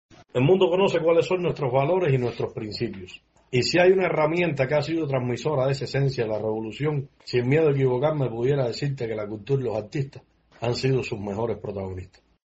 poeta repentista e improvisador